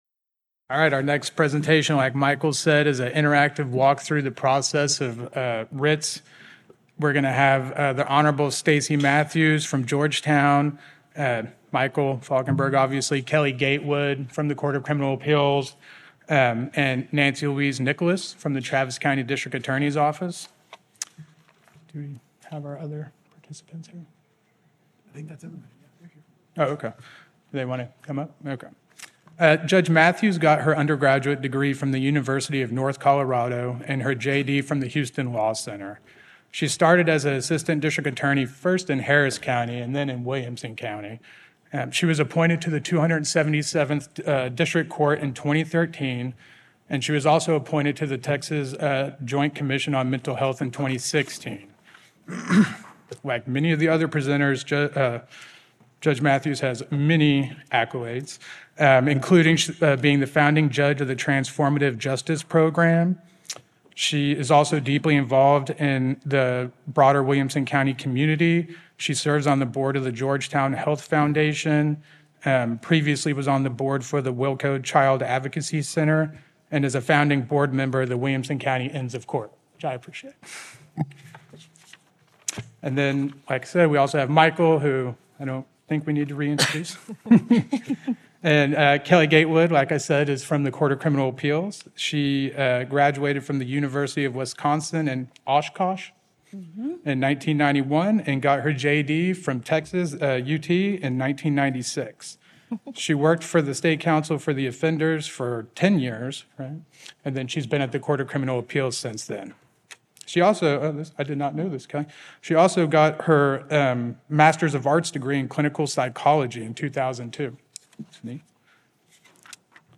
An interactive conversation with representatives from all sides of 11.07 writ litigation discussing the roles they play in the investigation, presentation, and decision in a post-conviction writ.
Originally presented: May 2024 Conference on Criminal Appeals